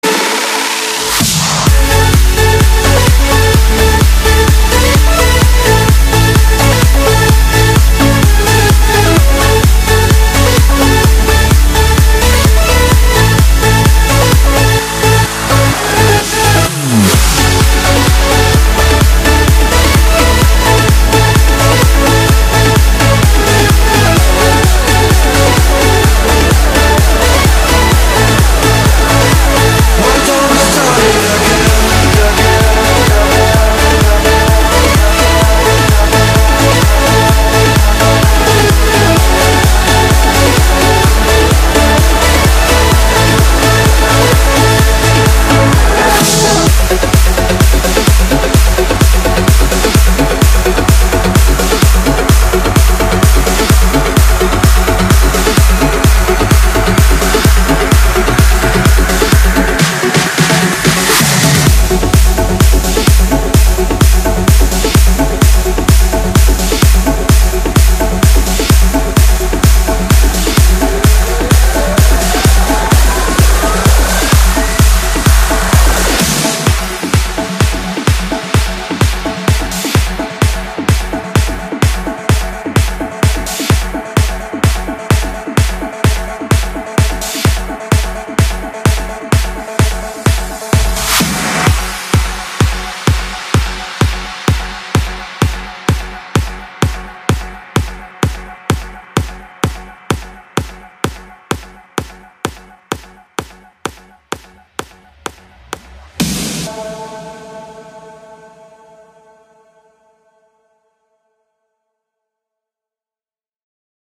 • Качество: 192, Stereo
progressive house
Стиль: Progressive house